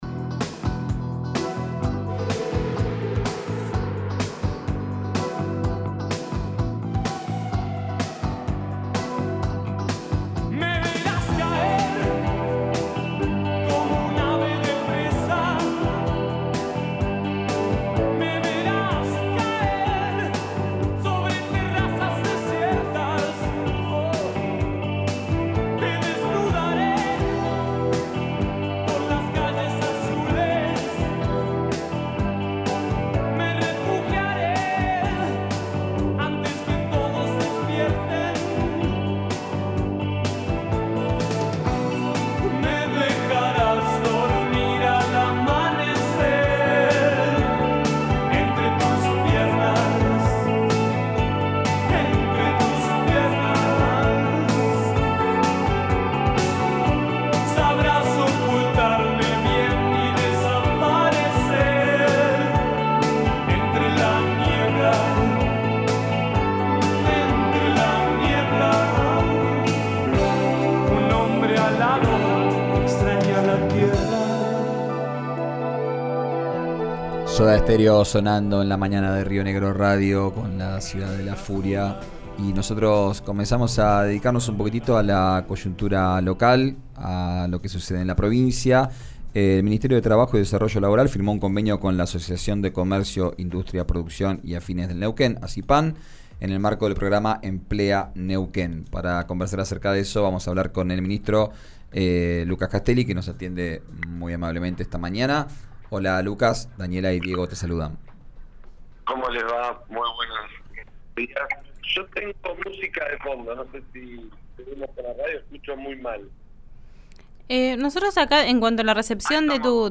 Escuchá al ministro de Trabajo y Desarrollo Laboral de Neuquén, Lucas Castelli, en RIO NEGRO RADIO: